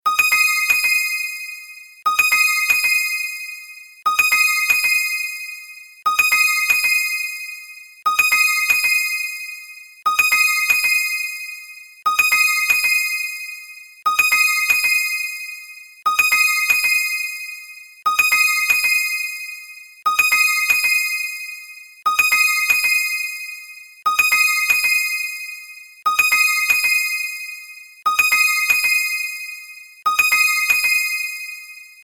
透明感のあるピアノの音色のシンプルな着信音。